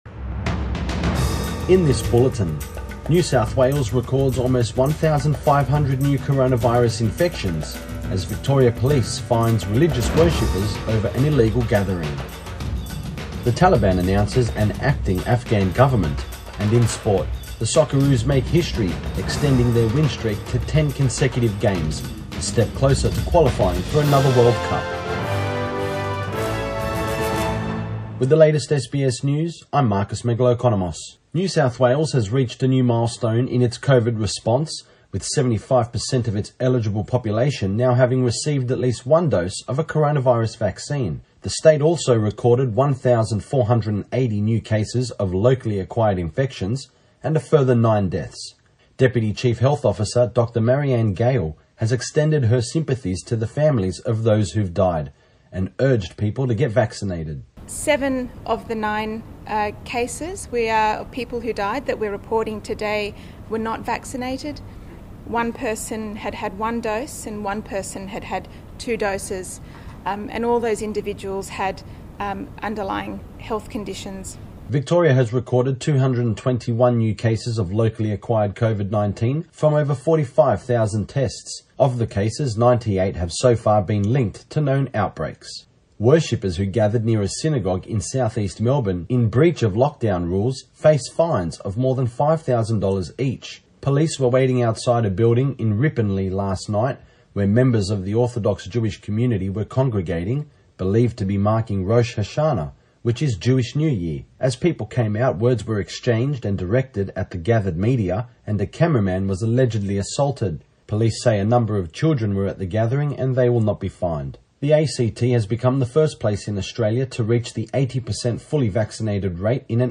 Midday bulletin 8 September 2021